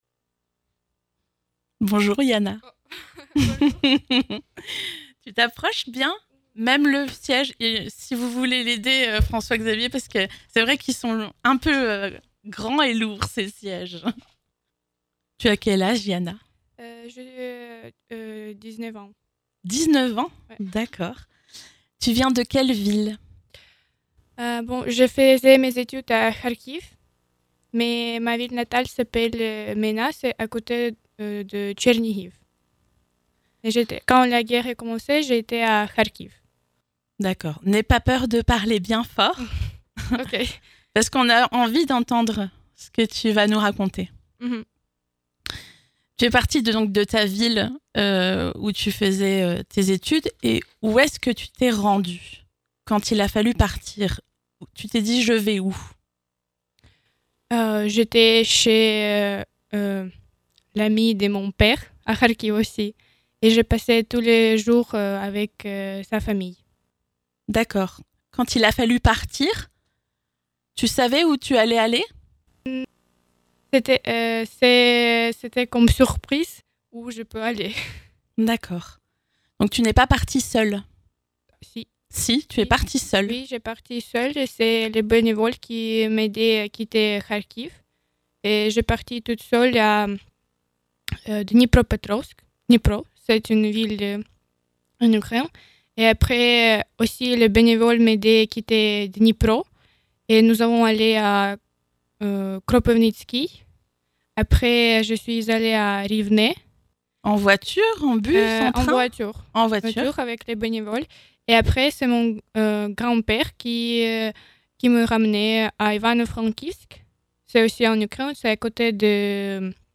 Interview Vosges FM